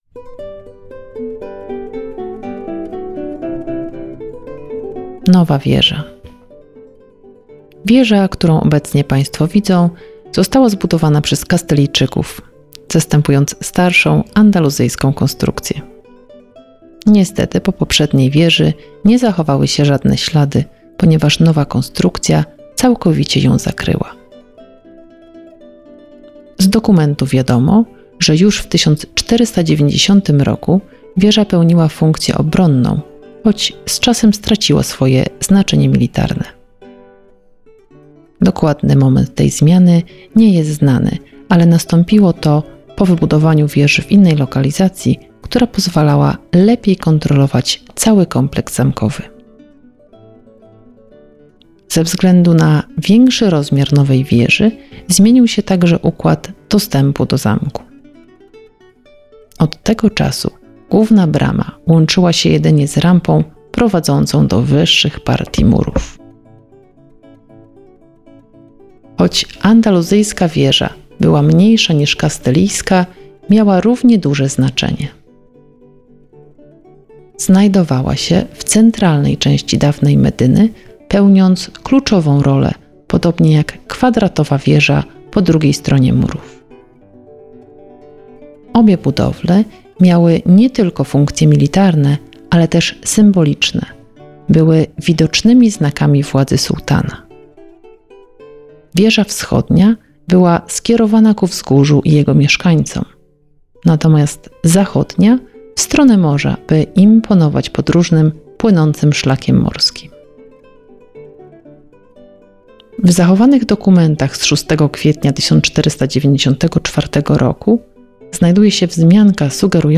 Zamek Salobreña – Zwiedzanie z audioprzewodnikiem